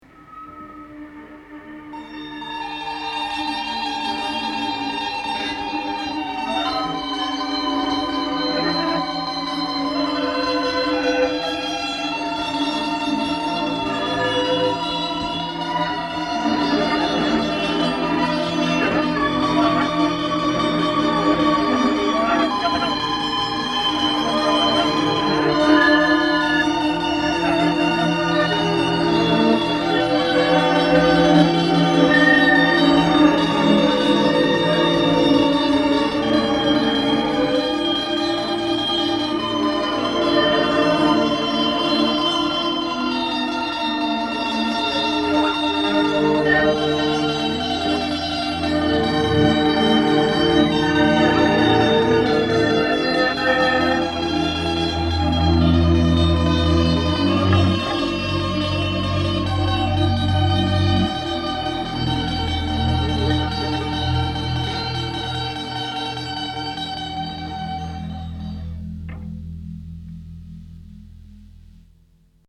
Four guitars and electric bass.